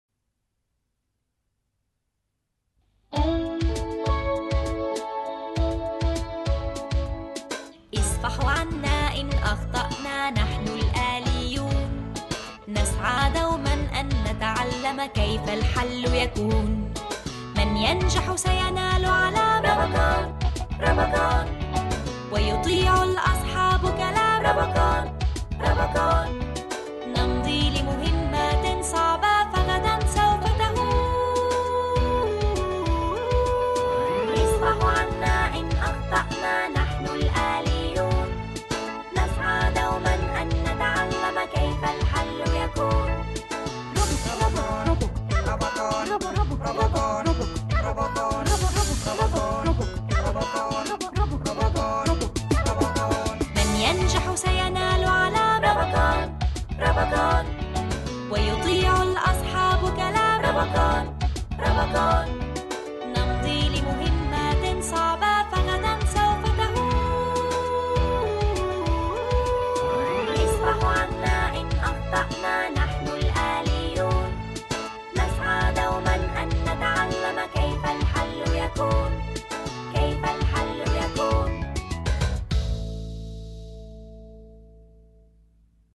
روبوكون - الحلقة 1 مدبلجة